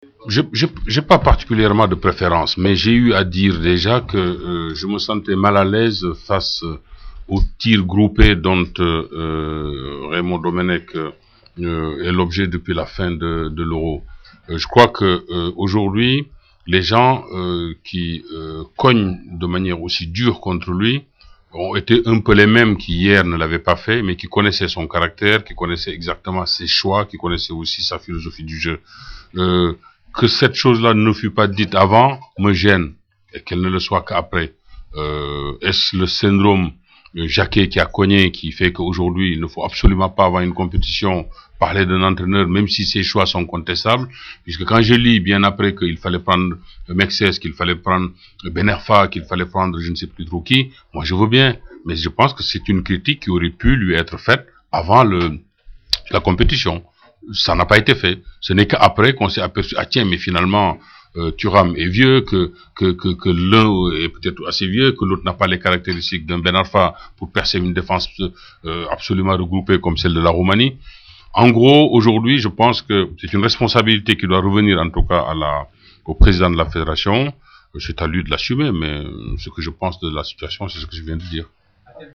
Pour ceux qui ne l’ont pas connu, parmi les plus jeunes fans de l’OM, ou les autres qui n’ont jamais entendu s’exprimer Pape Diouf dans son rôle de président de l’OM, voici quelques-unes de ses interventions parmi les plus savoureuses devant la presse à La Commanderie.